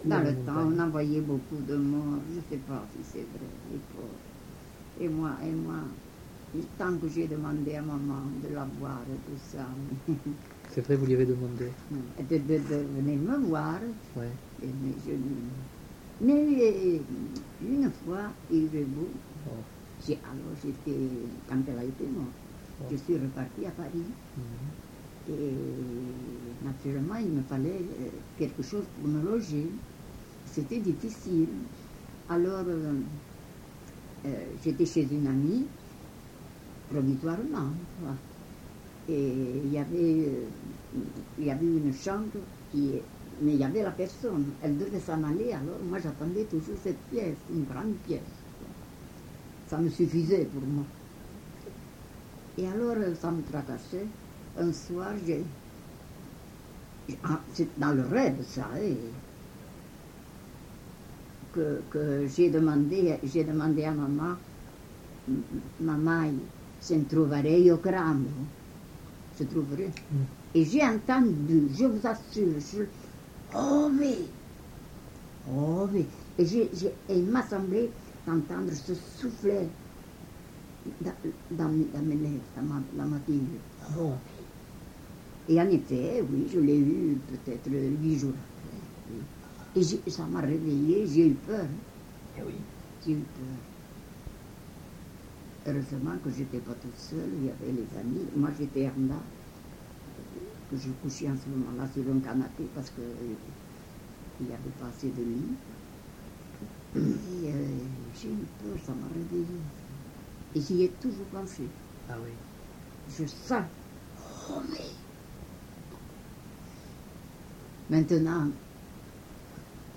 Aire culturelle : Couserans
Genre : conte-légende-récit
Classification : récit de peur